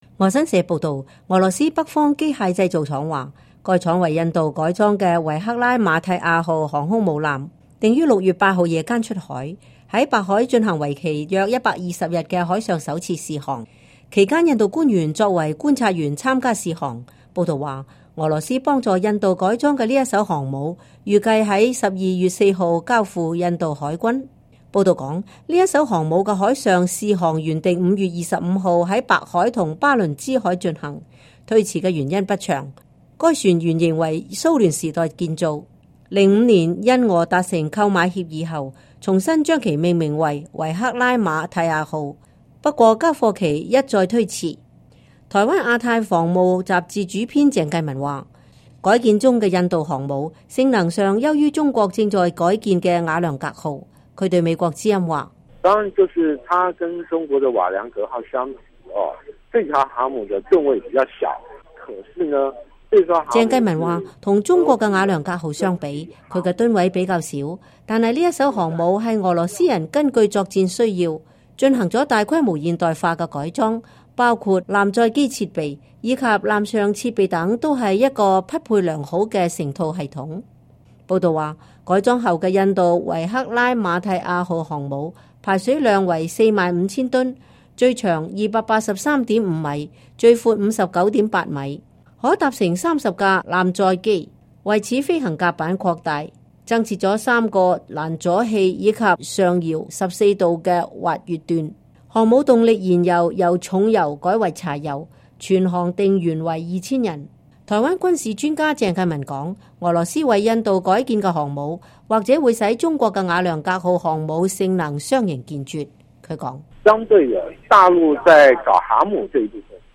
報導﹕俄羅斯幫助印度改建航空母艦